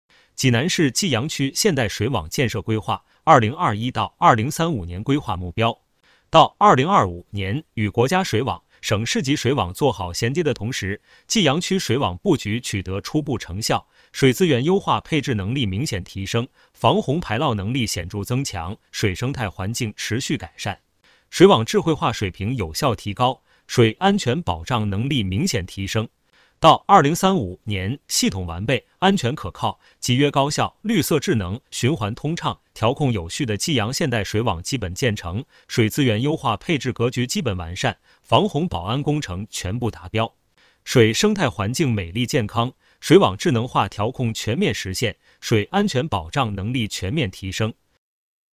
【有声朗读】《济南市济阳区人民政府关于印发济南市济阳区现代水网建设规划（2021-2035年）的通知》